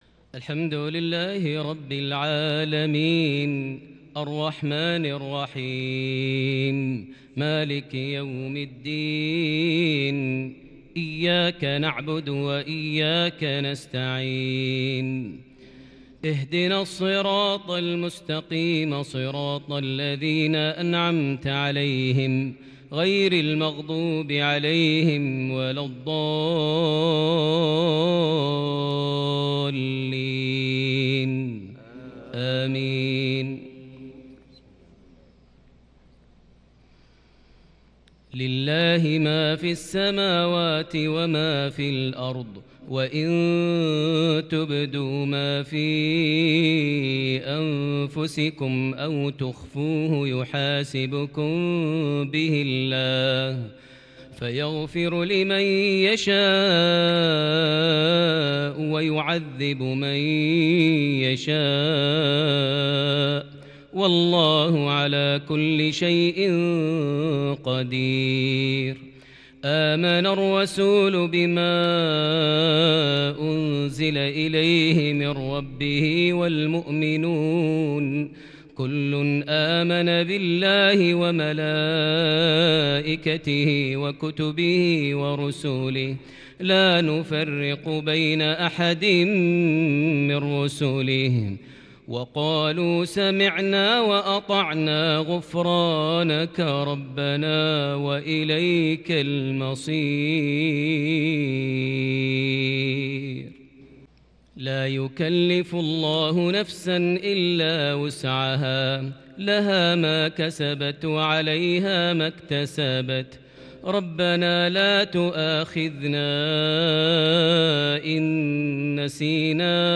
تلاوة شجية للغاية لخواتيم سورة البقرة مغرب 8 جمادى الآخر 1442هـ > 1442 هـ > الفروض - تلاوات ماهر المعيقلي